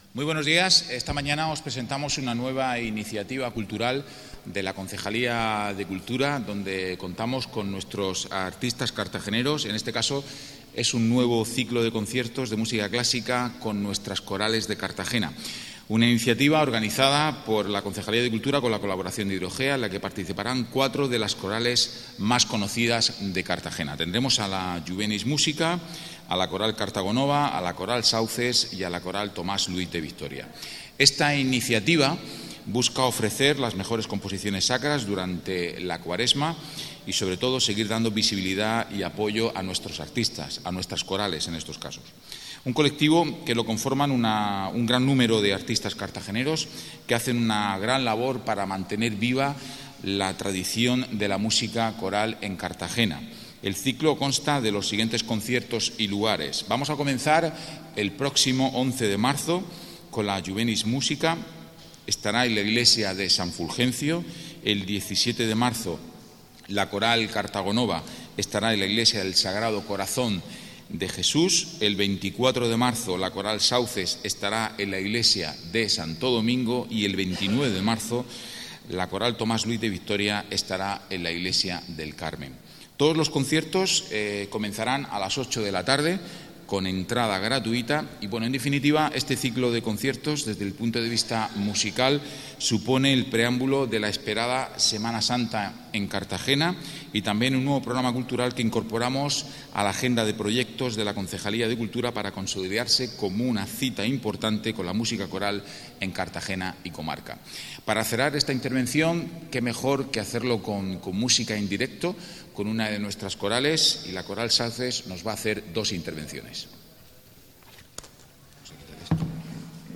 Enlace a Declaraciones Carlos Piñana